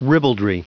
Prononciation du mot ribaldry en anglais (fichier audio)
Prononciation du mot : ribaldry